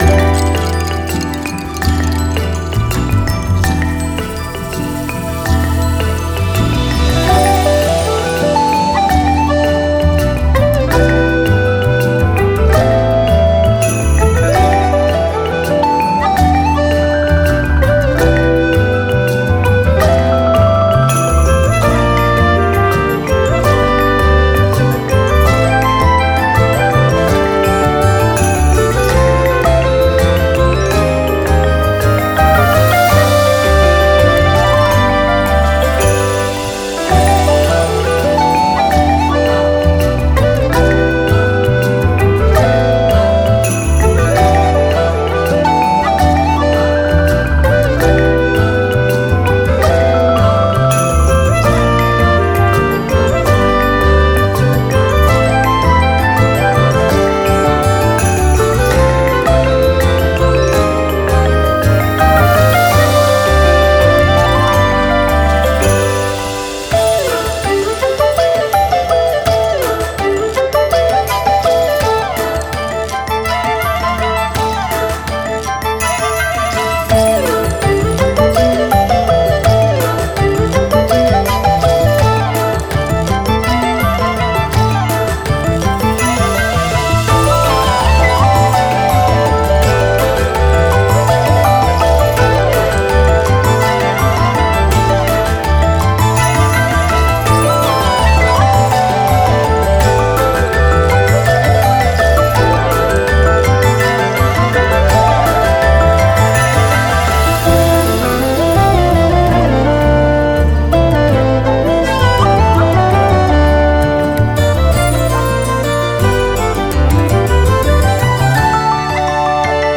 🎻ＢＧＭをご用意しました。